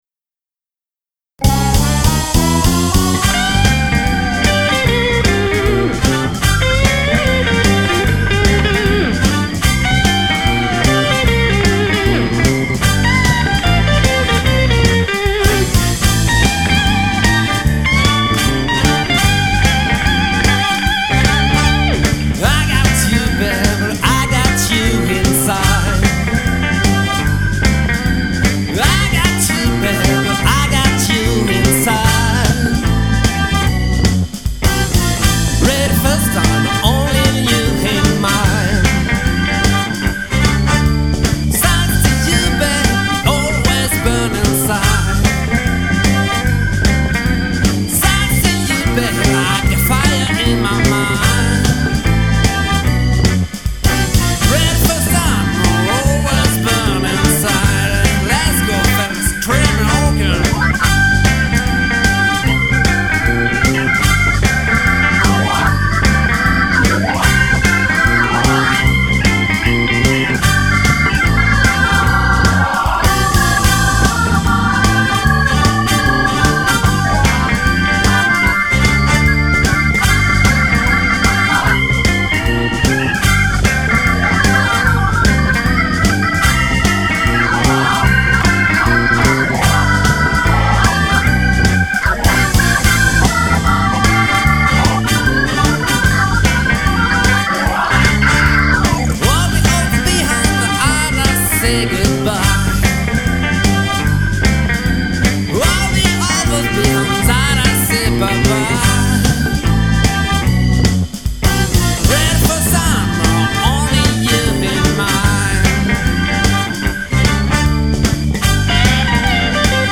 La nouvelle version sonne plus : plus de dynamique, son de guitare bien plus pèchu.
C'est seulement des maquettes pour que tout le monde puisse bosser.